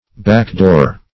Backdoor \Back"door"\, a.